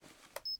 Camera_Equip.wav